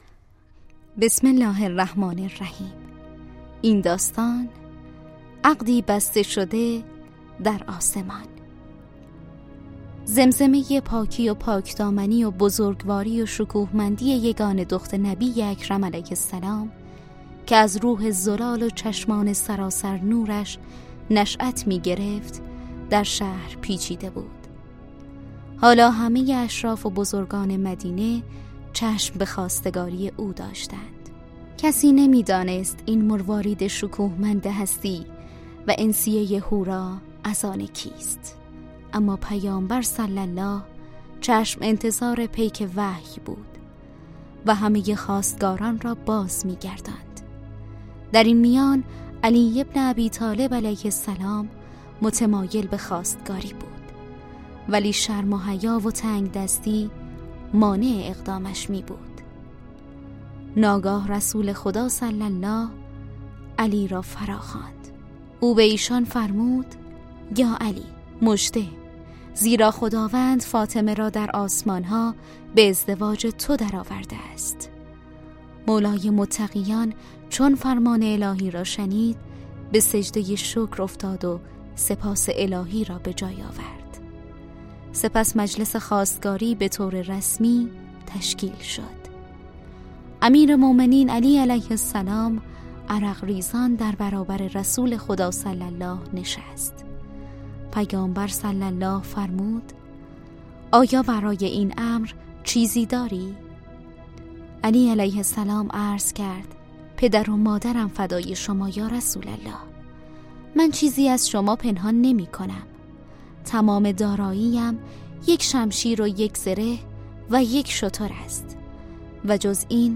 کتاب صوتی | مهربانو (14)
کتاب صوتی مهربانو